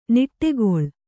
♪ niṭṭegoḷ